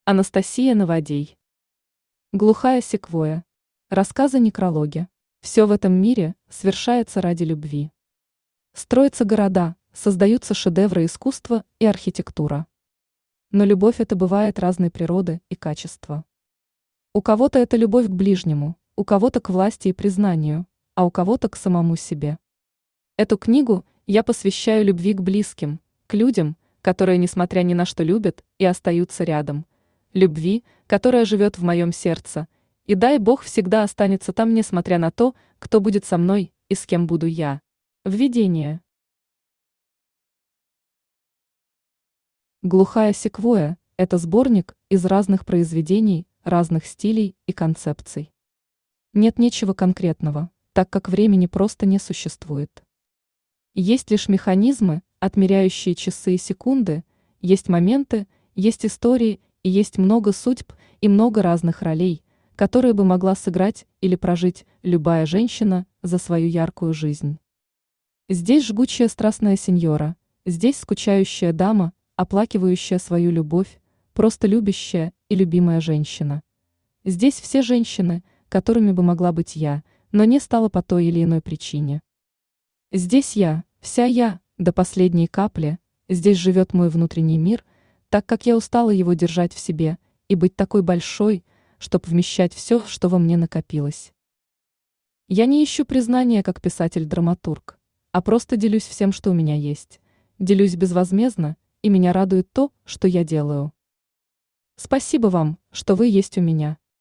Аудиокнига Глухая секвойя. Рассказы-некрологи | Библиотека аудиокниг
Рассказы-некрологи Автор Анастасия Наводей Читает аудиокнигу Авточтец ЛитРес.